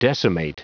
Prononciation du mot decimate en anglais (fichier audio)